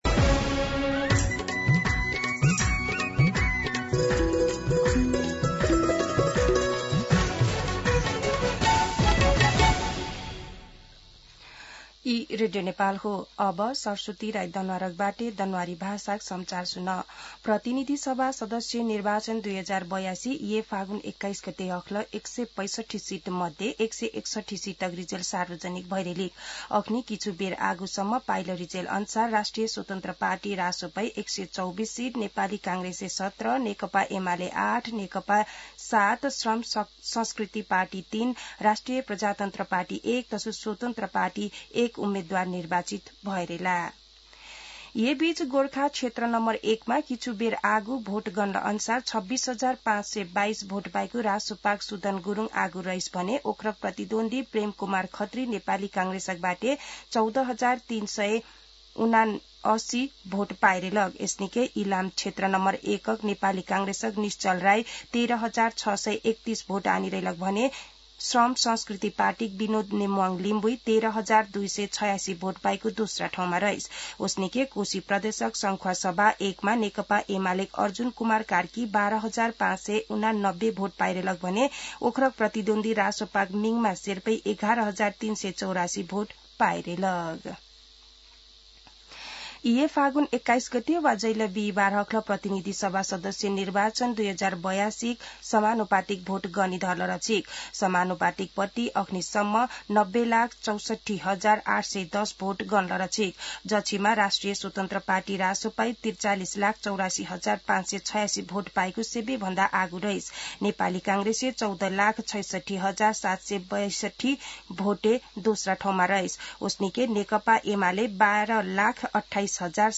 दनुवार भाषामा समाचार : २५ फागुन , २०८२
Danuwar-News.mp3